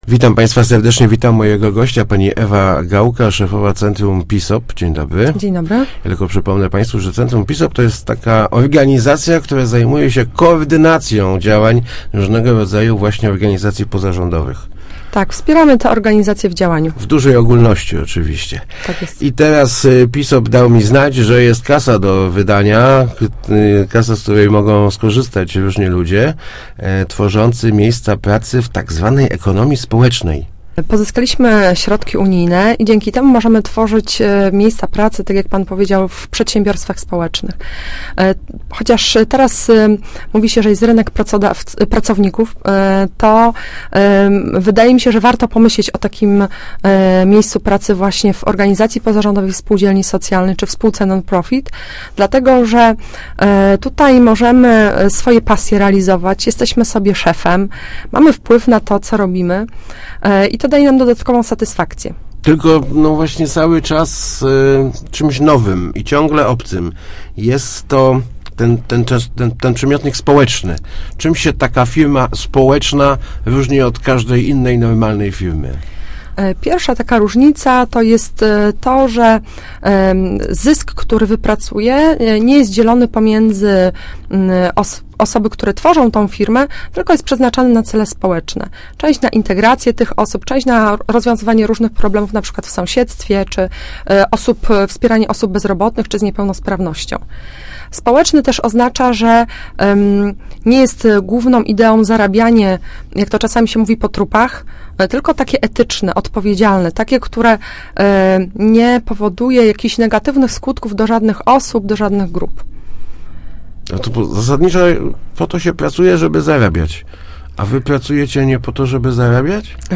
Start arrow Rozmowy Elki arrow Pieniądze na społeczny biznes